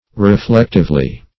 [1913 Webster] -- Re*flect"ive*ly, adv.